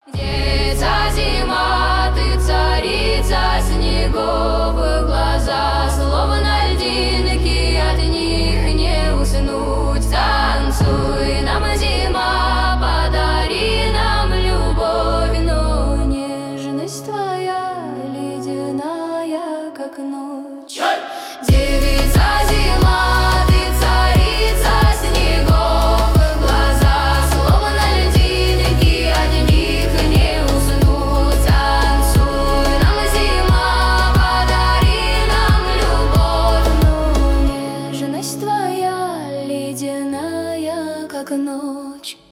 фолк , поп